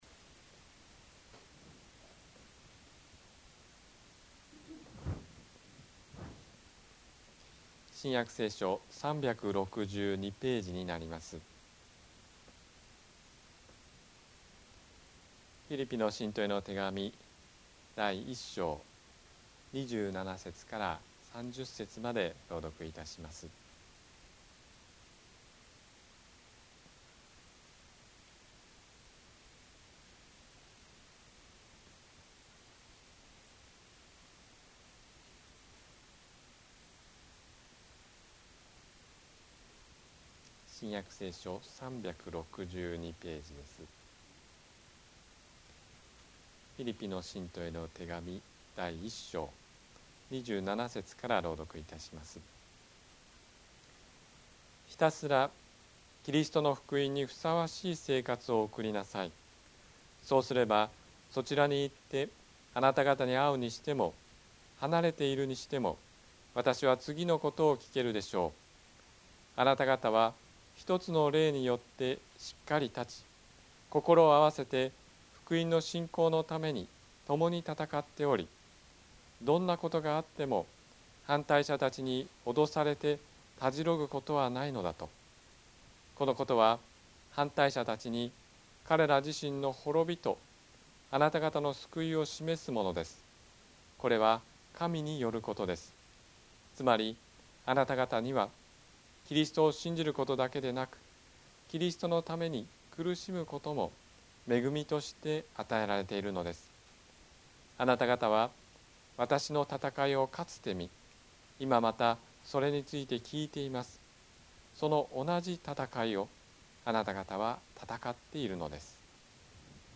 日曜 夕方の礼拝
説教